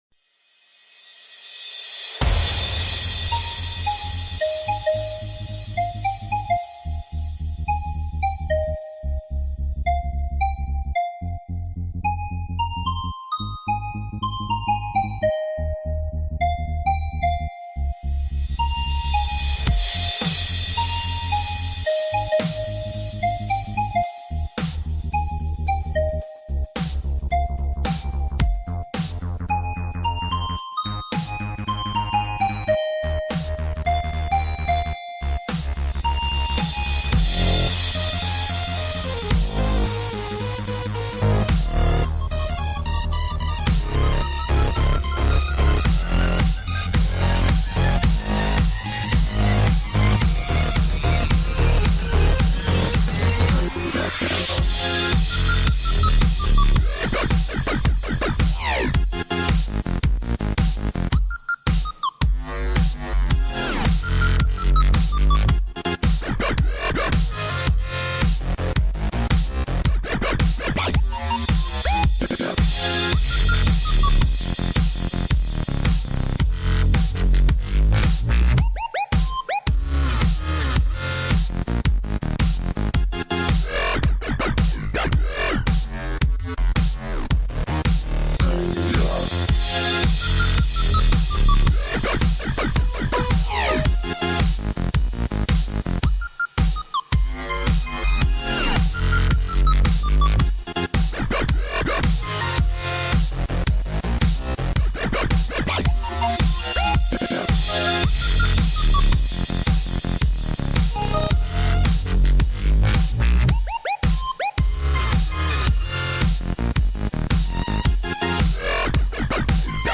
Here, have an attempt at some funky Glitch Hop.